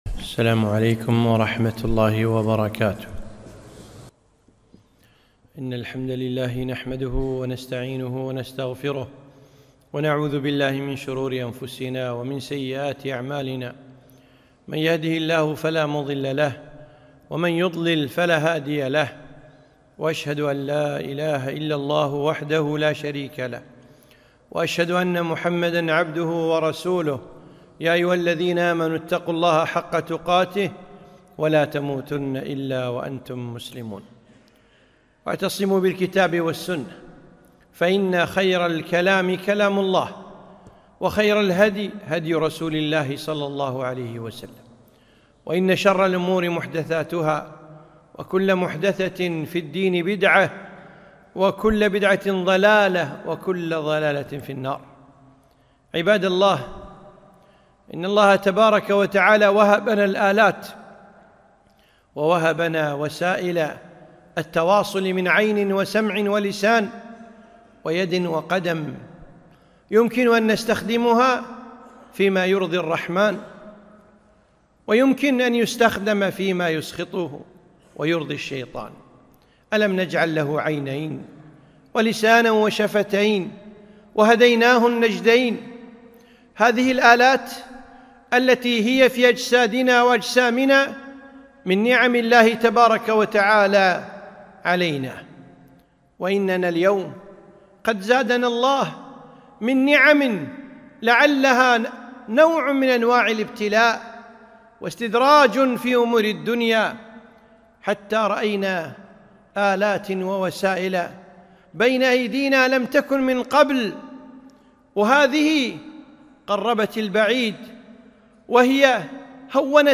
خطبة - ( التواصل )